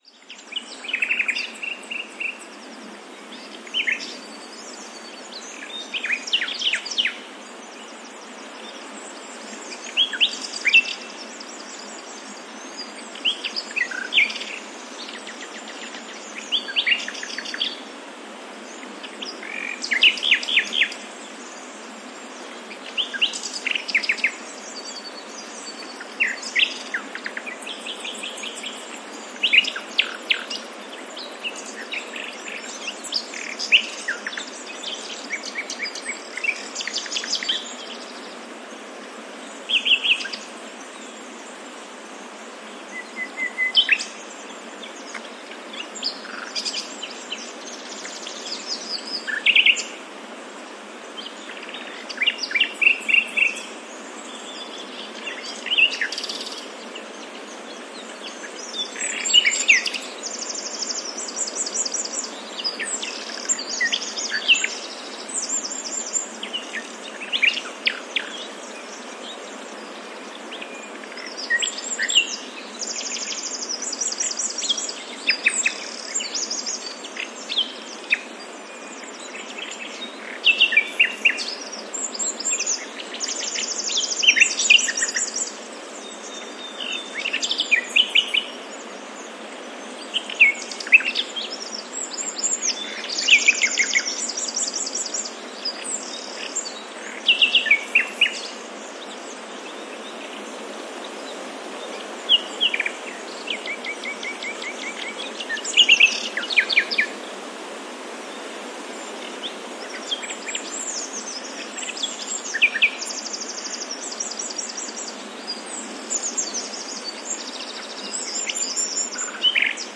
描述：夜莺在前景中唱歌，背景与风杂音+溪流+其他鸟类呼唤。 Audiotechnica BP4025内置飞艇，舒尔FP24前置放大器，PCMM10录音机。录制在La Macera附近（Valencia de Alcantara，Caceres，Spain）
标签： 风声 鸟声 夜莺 森林 氛围 春天 自然 现场录音
声道立体声